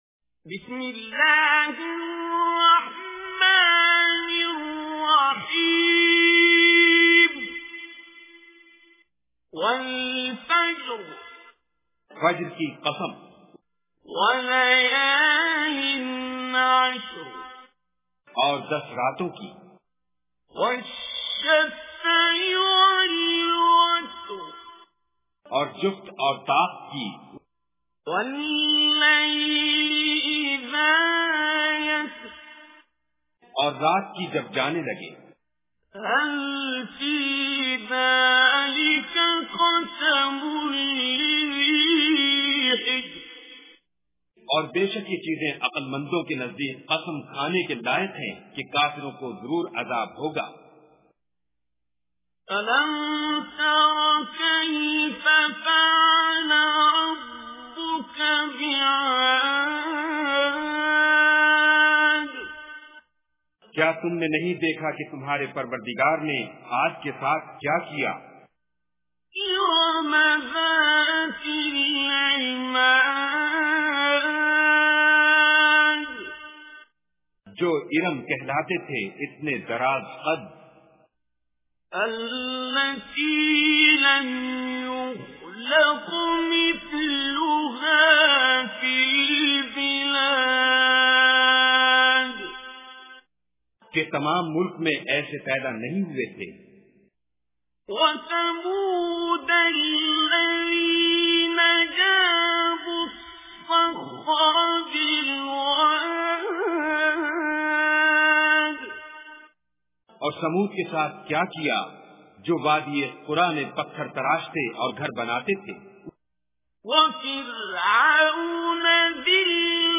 Surah Fajr Recitation with Urdu Translation
Surah Fajr is 89 chapter of Holy Quran. Listen online and download mp3 tilawat / recitation of Surah Fajr in the voice of Qari Abdul Basit As Samad.